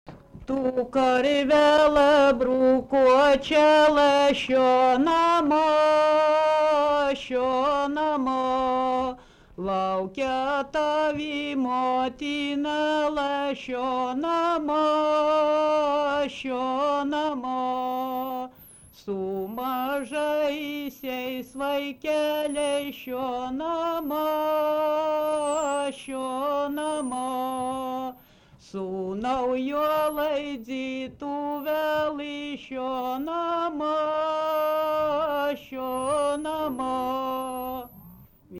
Subject daina
Erdvinė aprėptis Ryžiškė
Atlikimo pubūdis vokalinis